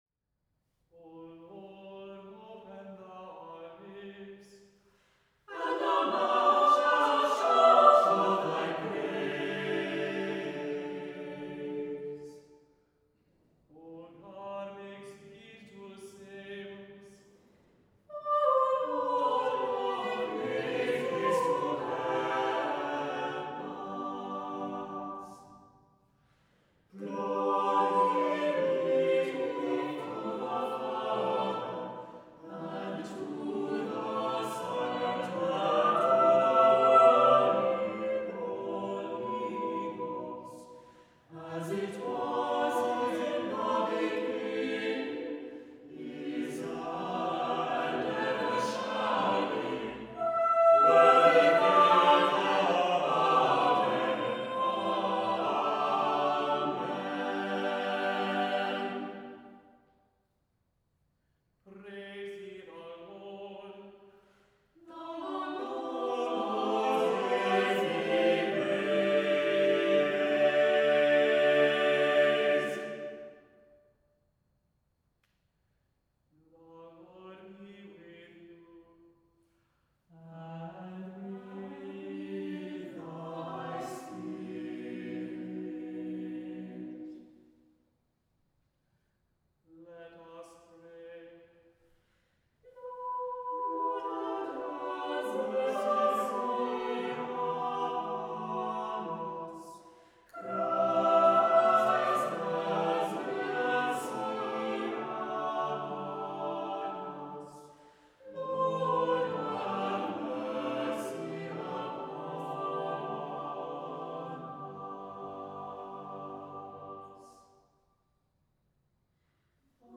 • Music Type: Choral
• Voicing: SATB
• Accompaniment: a cappella
• Liturgical Celebrations: Preces and Responses, Evensong